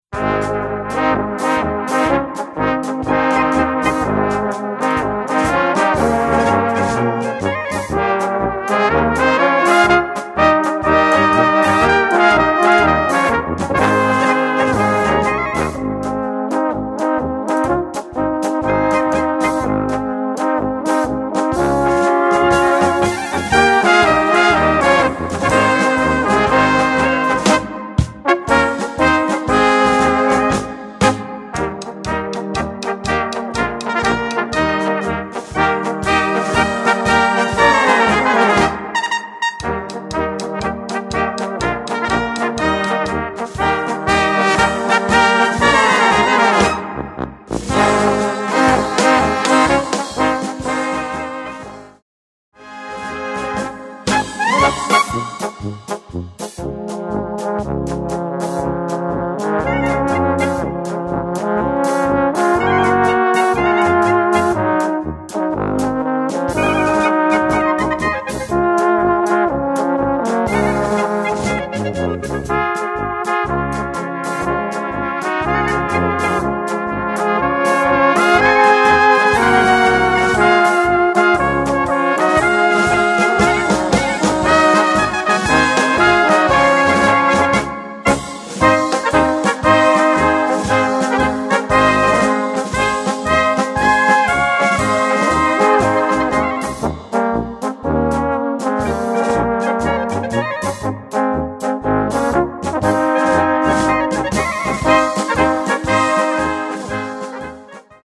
Gattung: Polka
Besetzung: Blasorchester